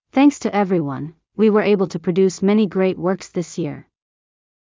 ここでは、例として「音読さん」を使った基本的なワークフローを紹介します。
今回は例として、英語ナレーションの速度と高低の違いで、2タイプ作ってみたとします。
音声１（速度：0.8、高低：0）→ 標準的で聞き取りやすく、汎用的な印象。